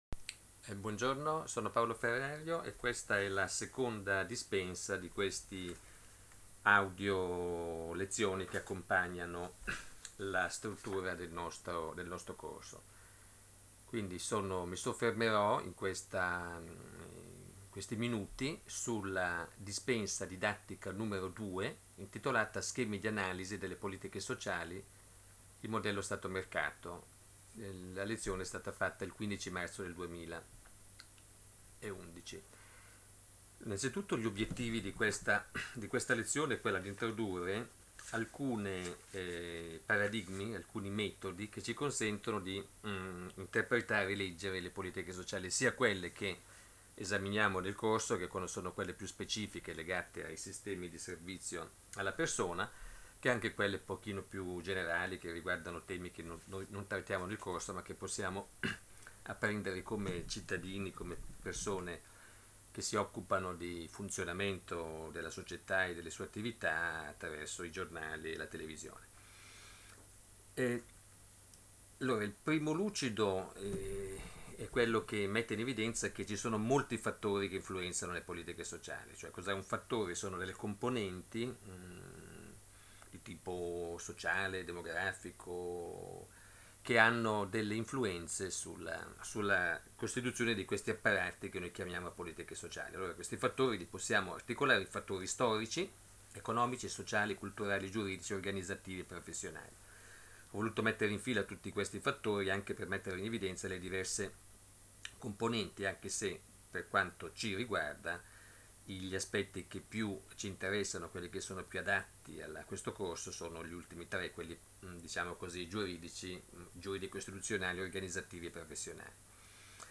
AUDIO LEZIONE: IL MODELLO STATO/MERCATO